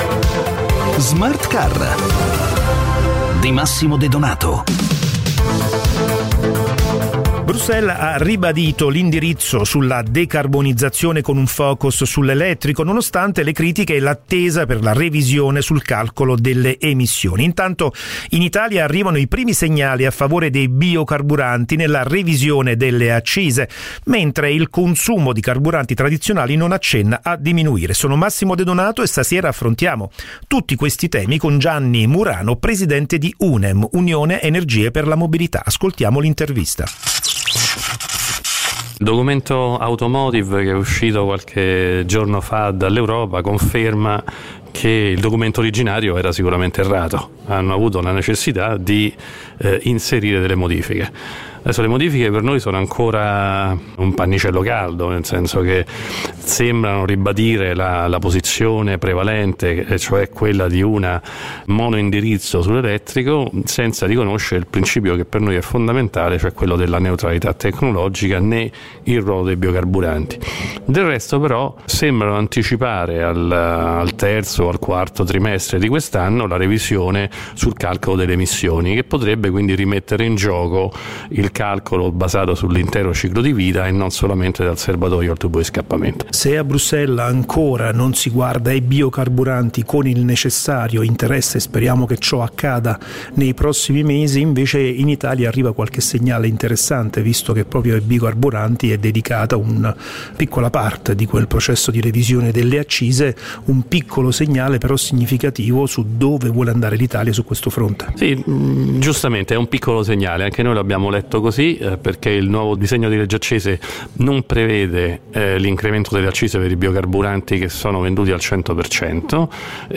Il ruolo dei biocarburanti nella transizione green. L’intervista